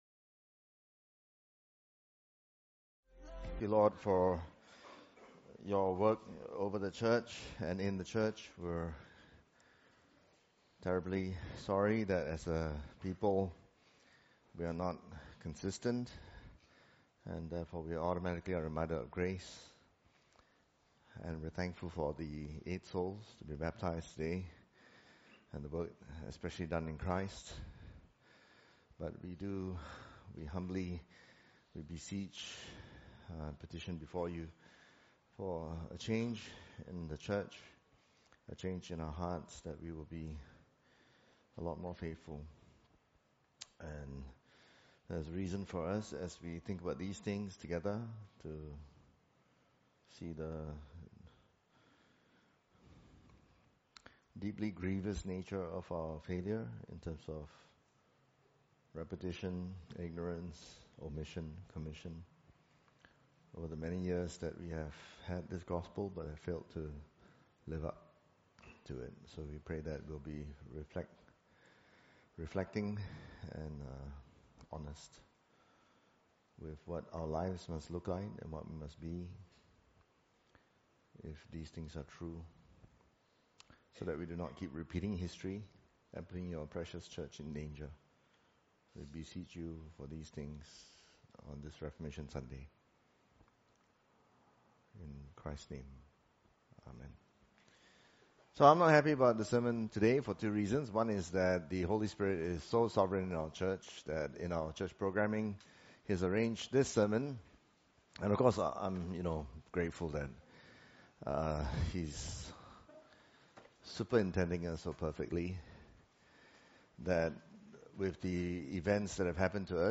Reformation Sunday: The Christian full of God. Deny all false alternatives. (Col 2:8-23) | Christ Evangelical Reformed Church (CERC)